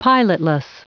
Prononciation du mot pilotless en anglais (fichier audio)